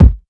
Kick
Original creative-commons licensed sounds for DJ's and music producers, recorded with high quality studio microphones.
Subby Kick Drum Single Shot A# Key 297.wav
subby-kick-drum-single-shot-a-sharp-key-297-Zhg.wav